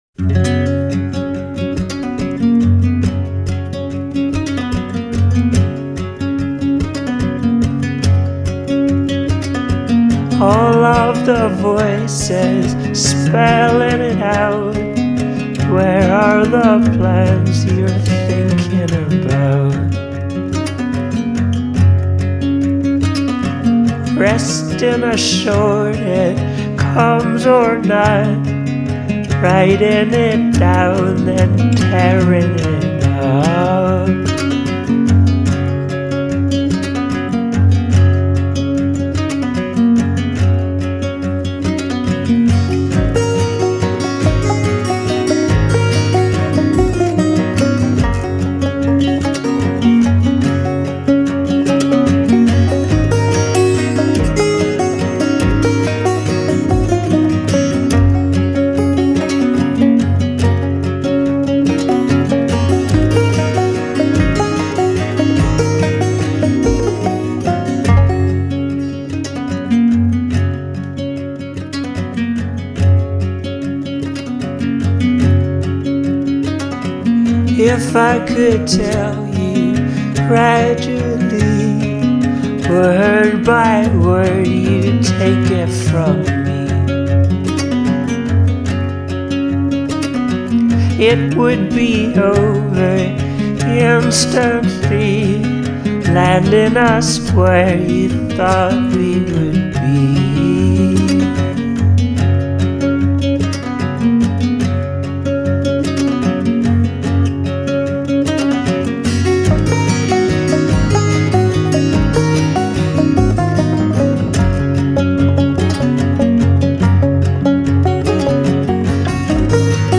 vocals, guitars and bass
guitars, bass and vocals
drums and chanting
bass and keys